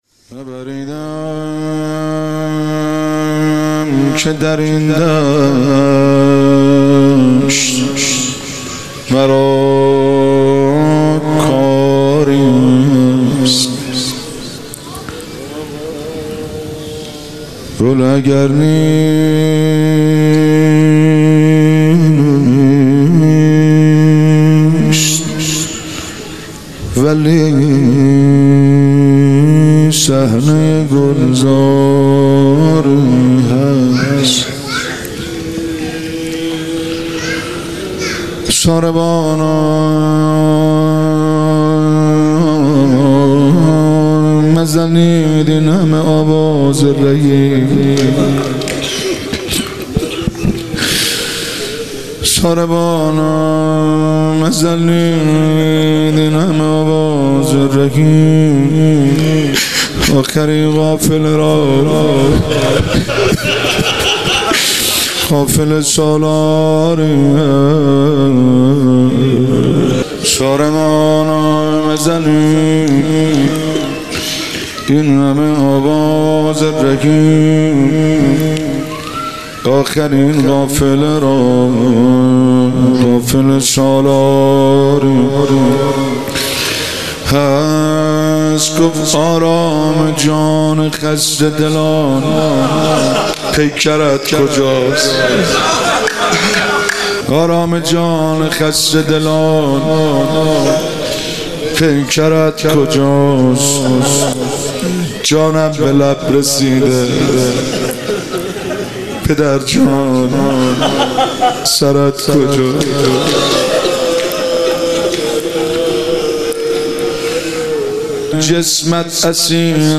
مراسم هفتگی4دی
روضه پایانی - نه بریدم که در این دشت کاری هست.